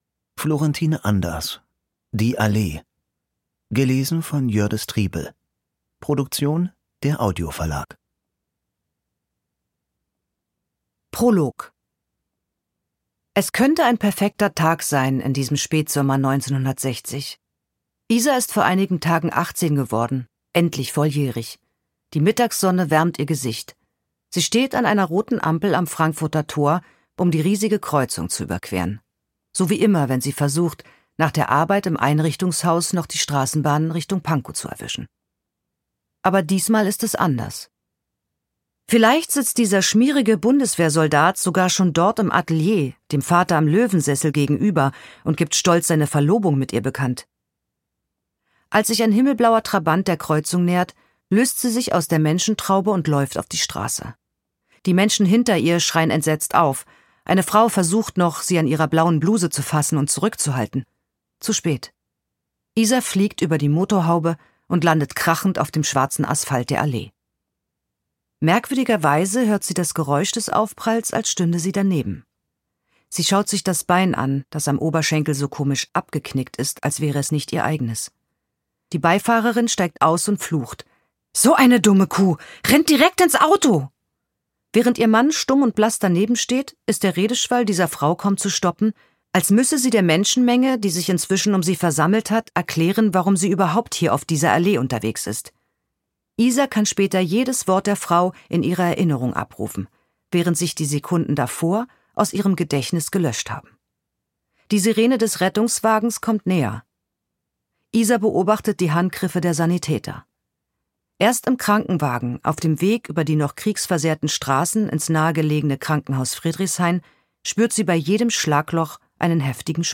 Ungekürzte Lesung mit Jördis Triebel (1 mp3-CD)
Jördis Triebel (Sprecher)